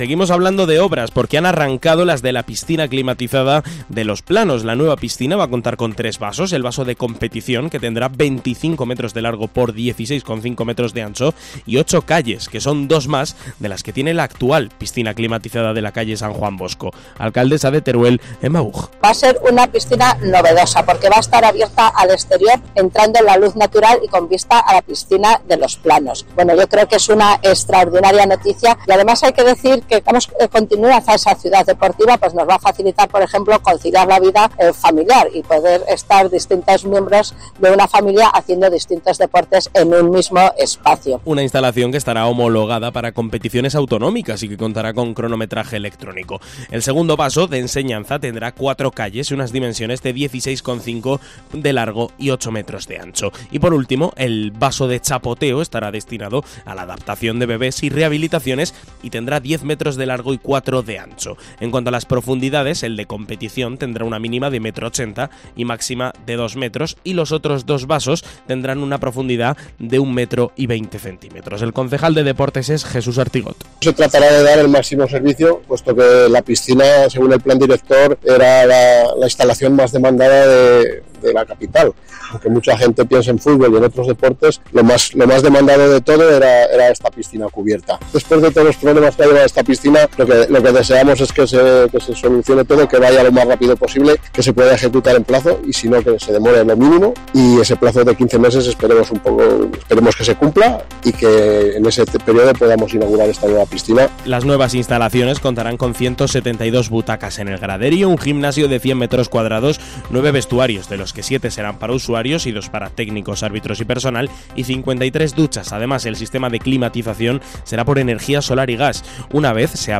La alcaldesa de Teruel, Emma Buj y el concejal de Deportes, Jesús Artigot, valoran las obras